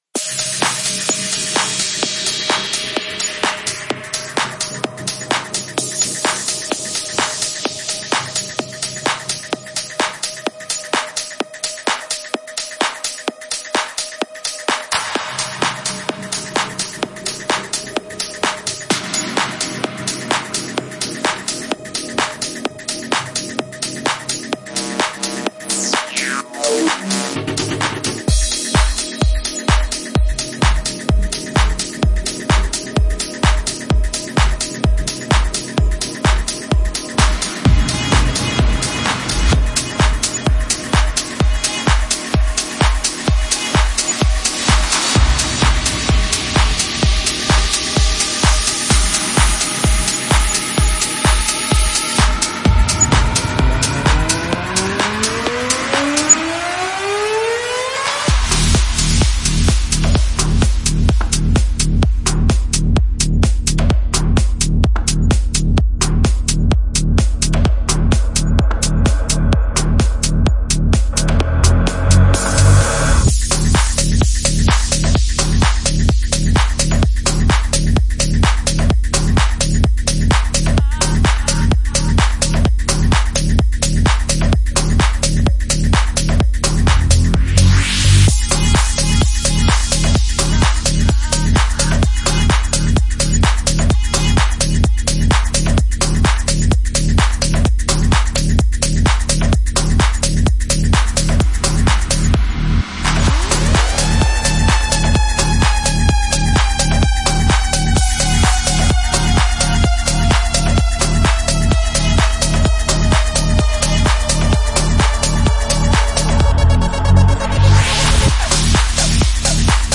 • Techno selection with tracks by